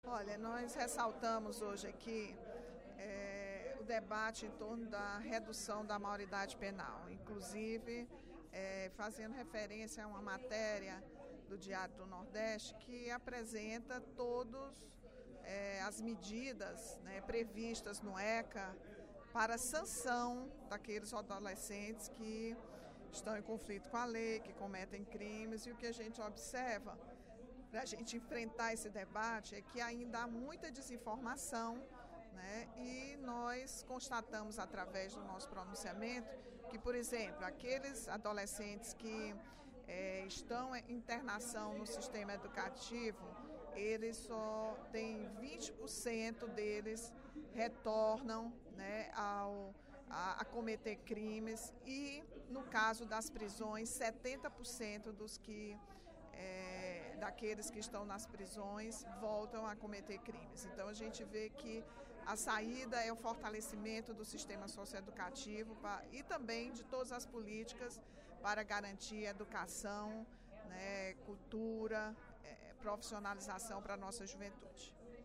A deputada Rachel Marques (PT) destacou, durante o primeiro expediente da sessão plenária desta quarta-feira (01/07), a derrota da Proposta de Emenda à Constituição (PEC) que diminuía a maioridade penal de 18 para 16 anos para crimes graves.